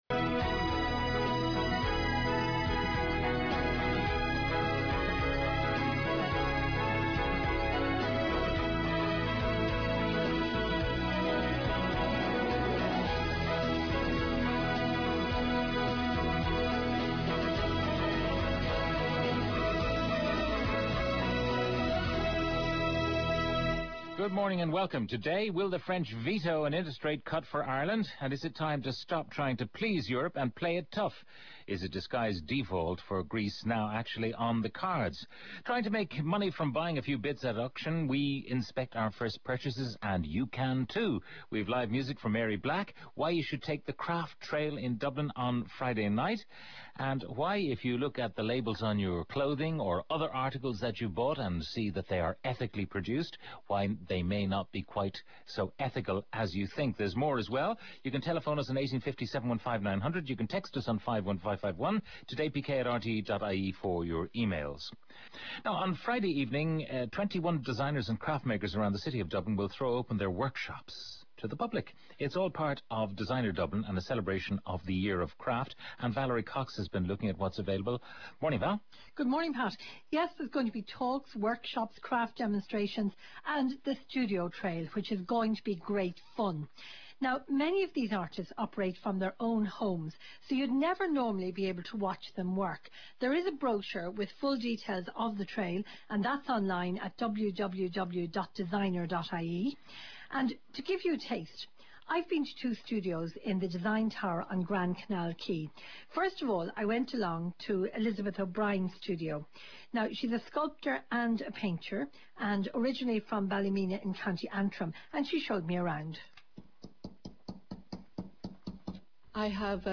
Pat Kenny Show.mp3